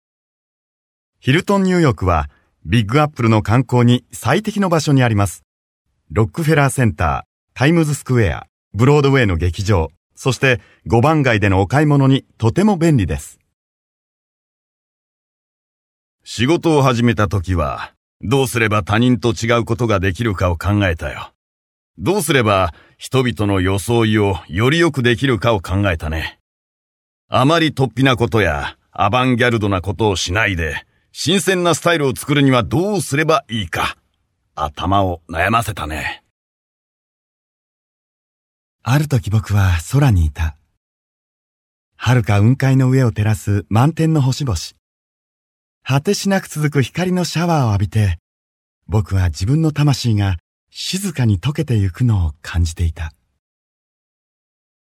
日语样音试听下载
Jp-male--DJ007-demo.mp3